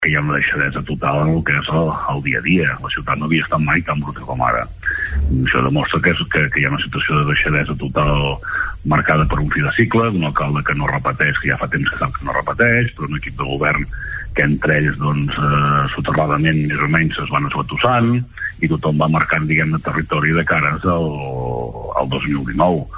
Entrevistes Supermatí